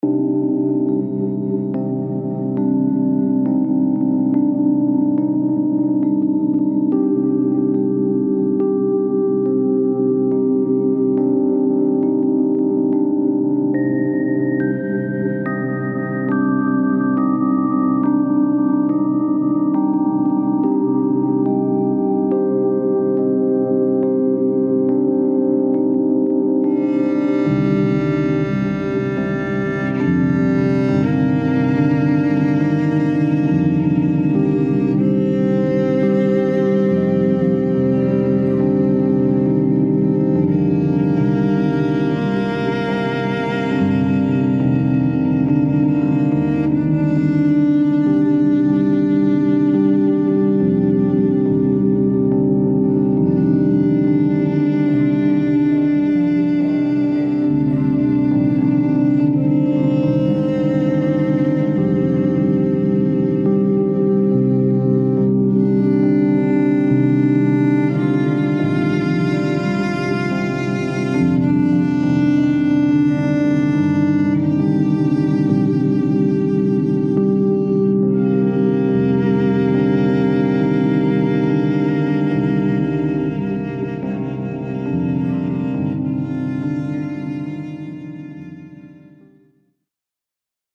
Calming and comforting